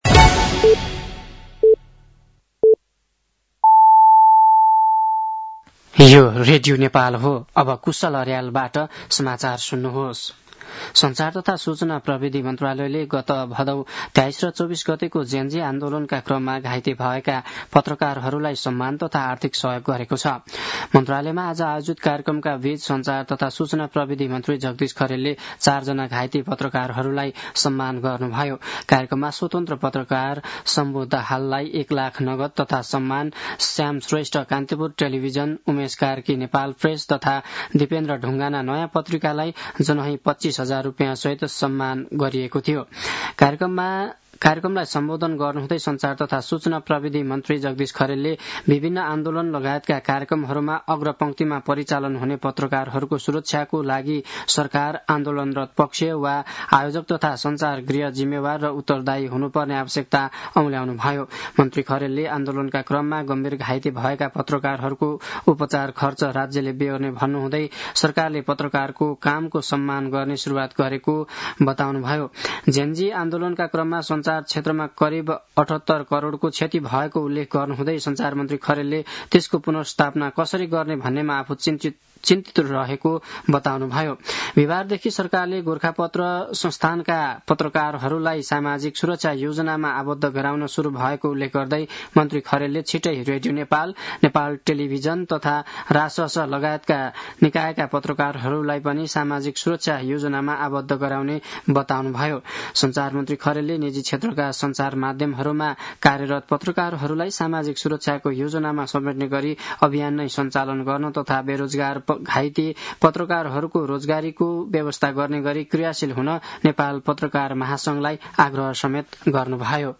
साँझ ५ बजेको नेपाली समाचार : ४ पुष , २०८२
5-pm-nepali-news-9-04.mp3